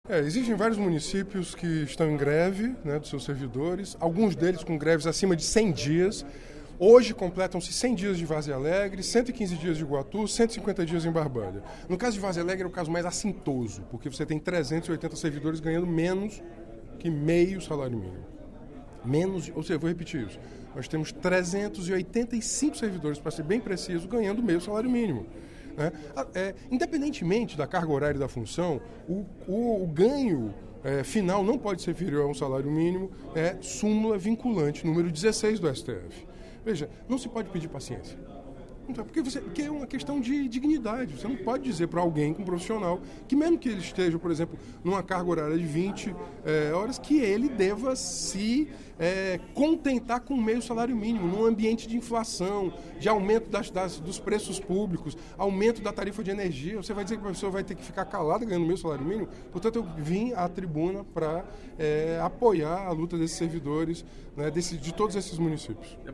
O deputado Renato Roseno (Psol) defendeu, no primeiro expediente da sessão plenária desta quarta-feira (23/09), a luta dos servidores públicos de alguns municípios cearenses.